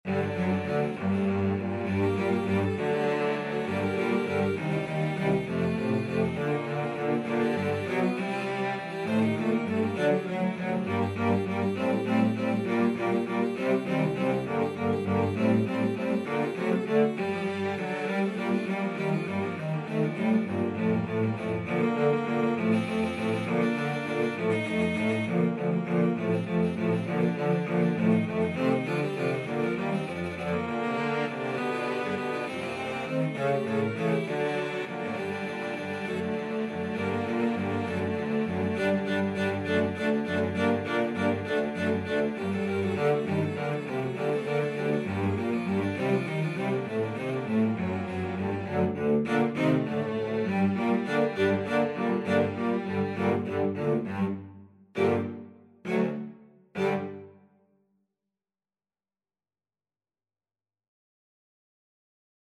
Cello 1Cello 2Cello 3Cello 4Cello 5
3/8 (View more 3/8 Music)
Presto (View more music marked Presto)
Classical (View more Classical Cello Ensemble Music)